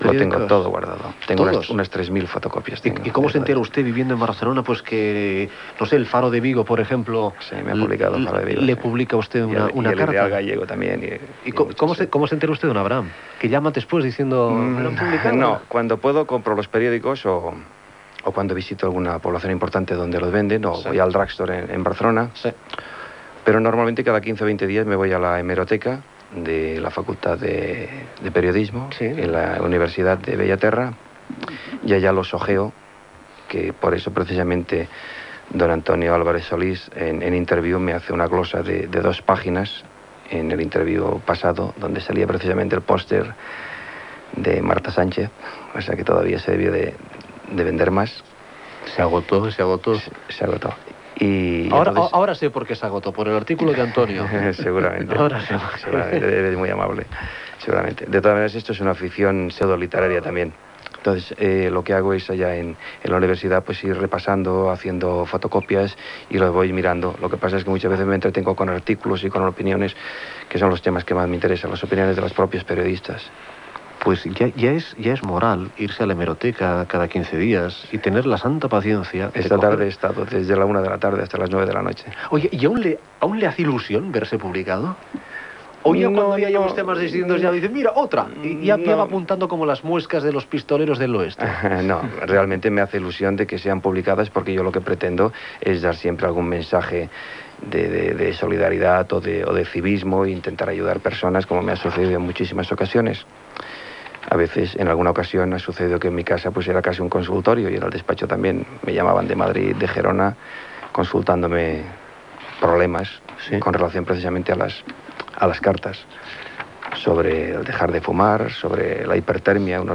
Diàleg
Comiat del programa amb els noms de l'equip Gènere radiofònic Entreteniment